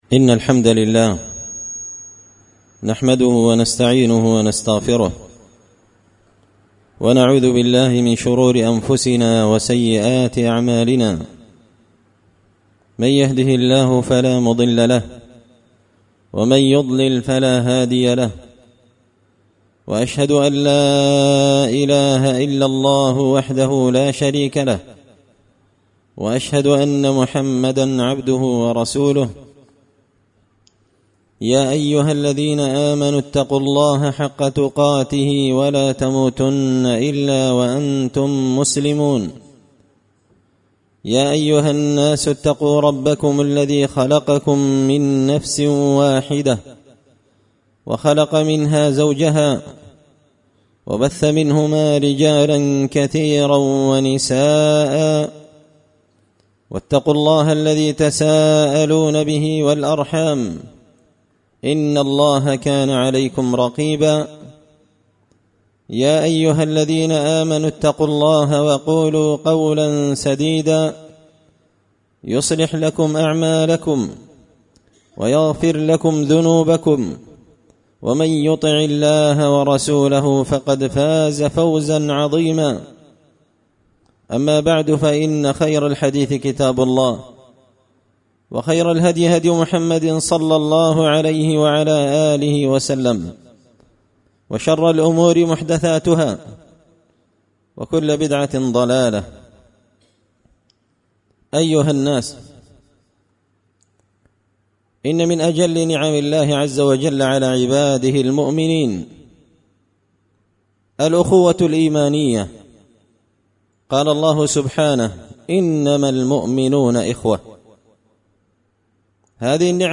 خطبة جمعة بعنوان – لزوم الجماعة
دار الحديث بمسجد الفرقان ـ قشن ـ المهرة ـ اليمن